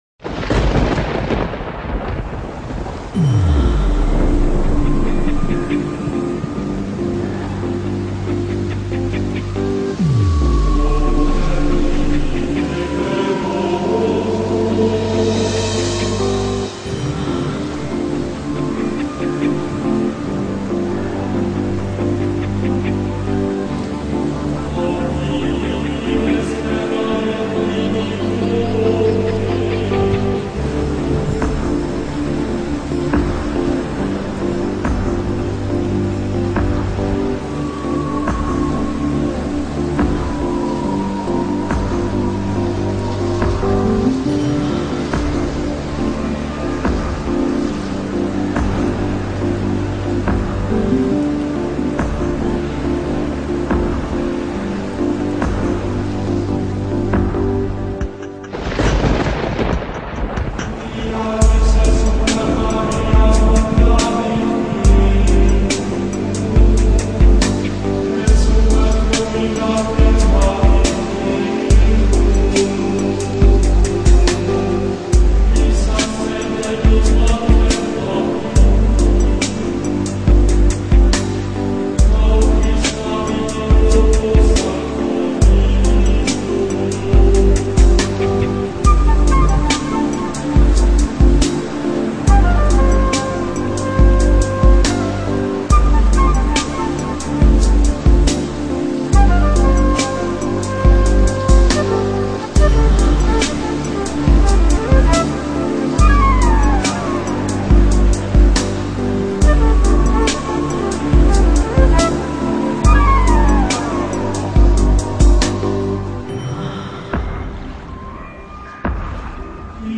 Chill Out、Lounge 音乐